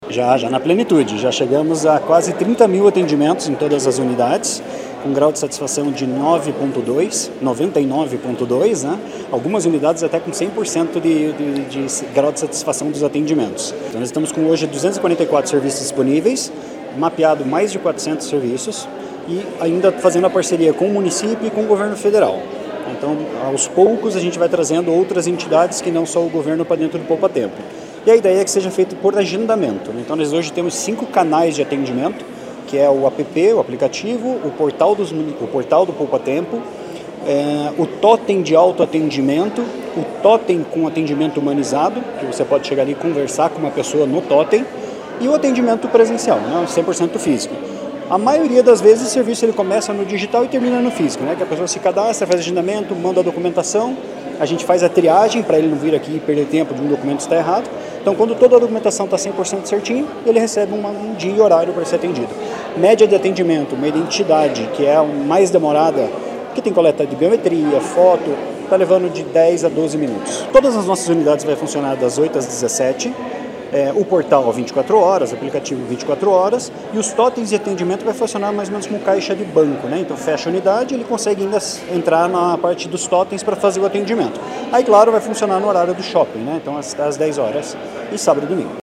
Sonora do Superintendente-geral de Governança, Serviços e Dados, Leandro Moura, sobre a segunda unidade do Poupatempo Paraná em Curitiba